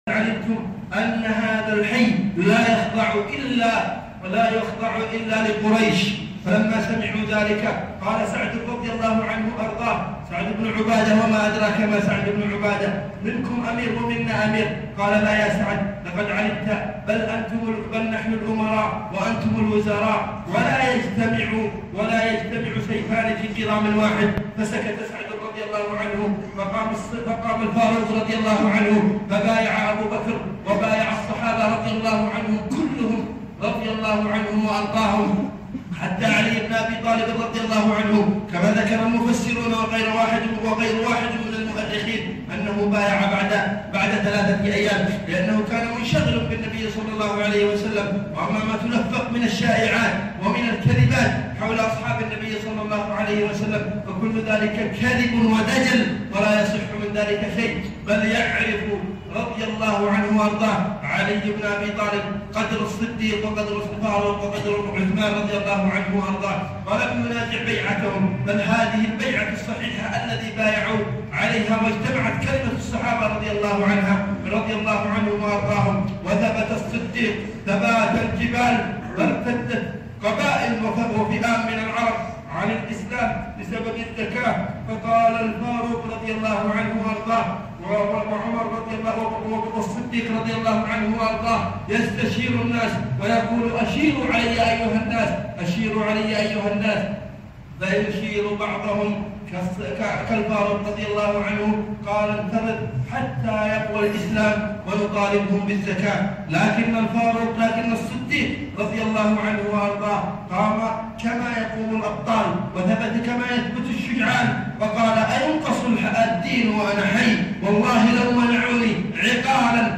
خطبة الجمعة في جامع المصري بمدينة مارب اليمن